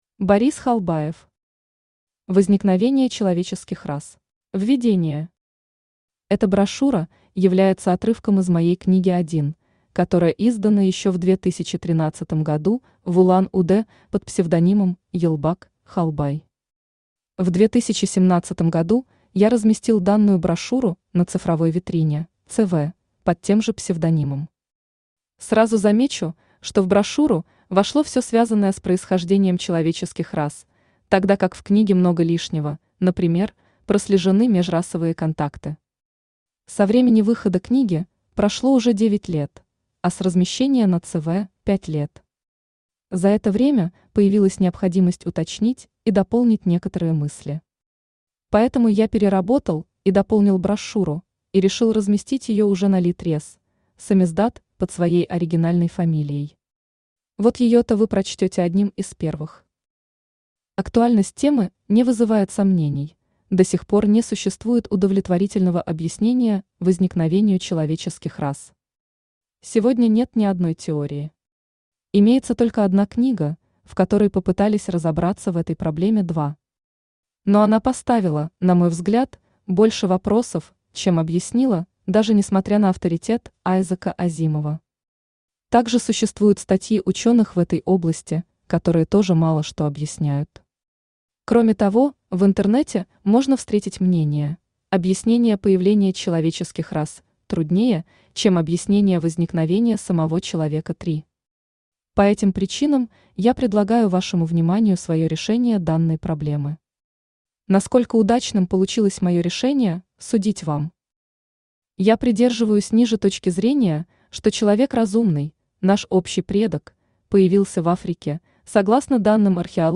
Аудиокнига Возникновение человеческих рас | Библиотека аудиокниг
Aудиокнига Возникновение человеческих рас Автор Борис Халбаев Читает аудиокнигу Авточтец ЛитРес.